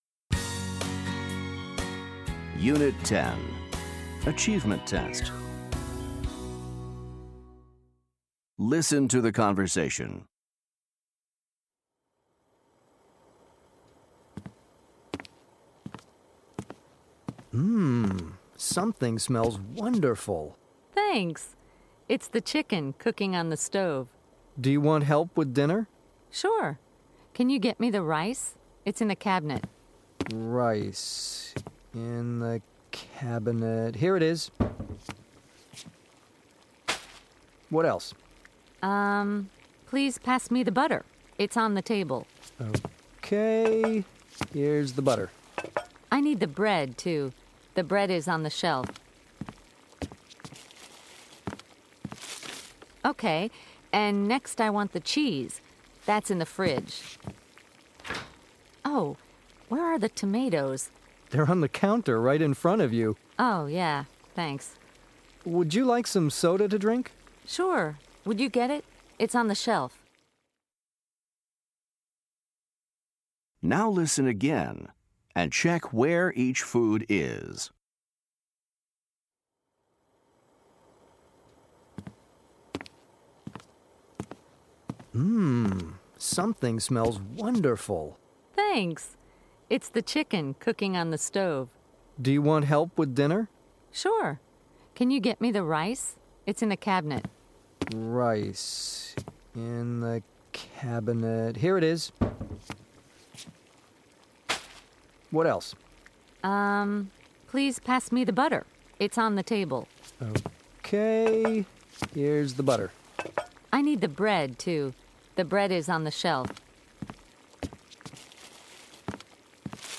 Listen to the conversation. Then listen again and select where each food is.